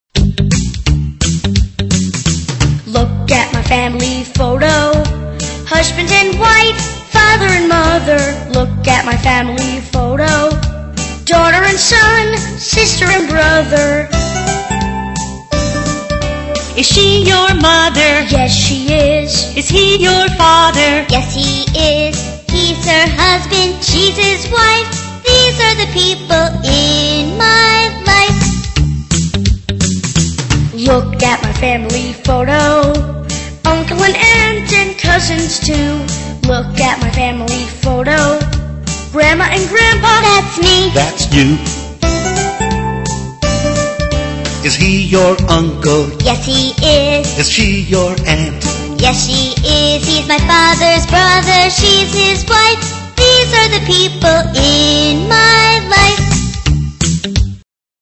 在线英语听力室英语儿歌274首 第129期:Look at my family photo的听力文件下载,收录了274首发音地道纯正，音乐节奏活泼动人的英文儿歌，从小培养对英语的爱好，为以后萌娃学习更多的英语知识，打下坚实的基础。